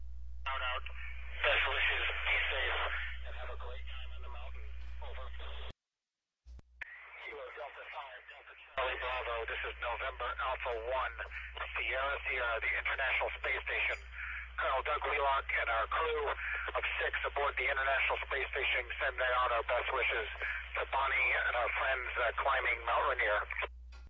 NA1SS Douglas Wheelock in contact with KD5DCB Dr. Bonnie J. Dunbar
Commander Douglas Wheelock on the International Space Station (NA1SS) was in contact with her.